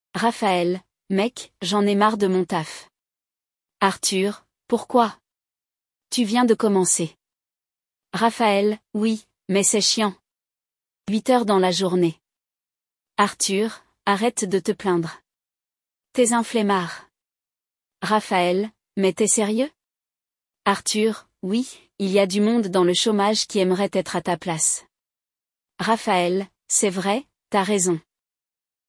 No diálogo deste episódio, dois amigos estão conversando: um deles não parece estar muito satisfeito com seu trabalho, então, vai reclamar com o amigo.